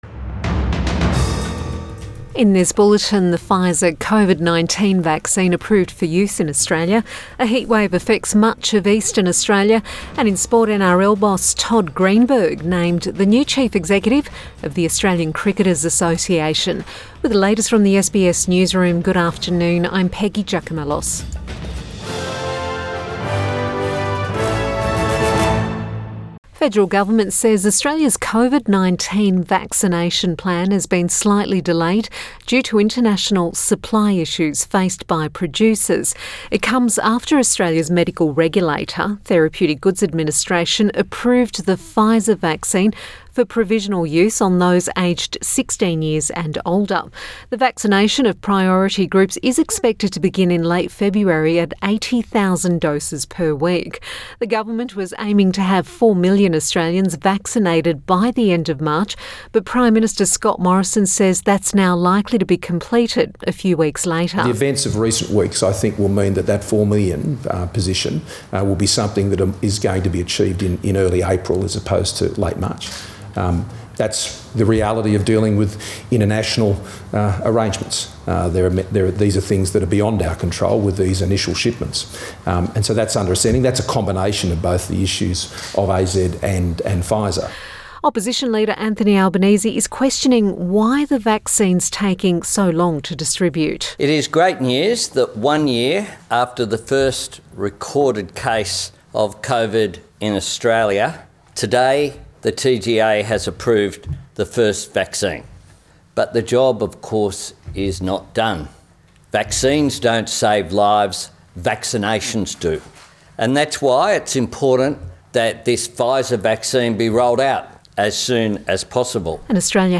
PM bulletin 25 January 2020